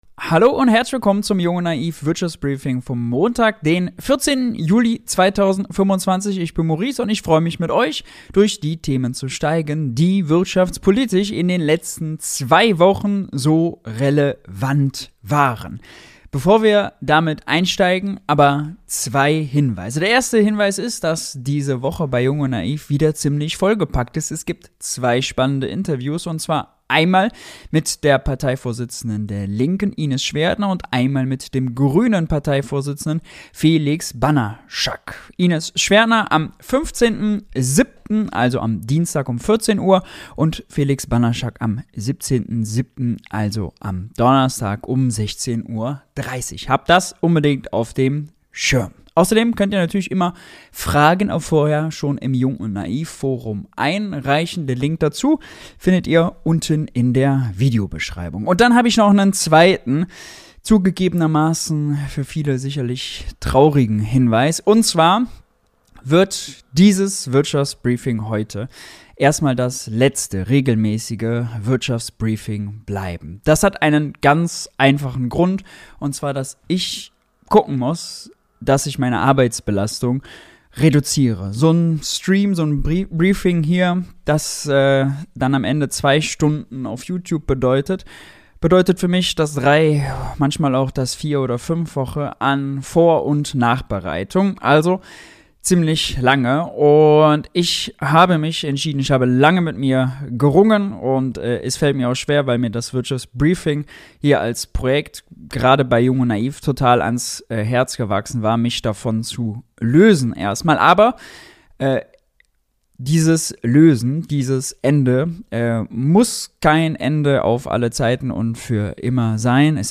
1 S2 Bonus: Das ganze Interview mit Christian Drosten 1:19:17
Das hört ihr im Interview mit Virologe Christian Drosten, Institutsdirektor an der Charité in Berlin.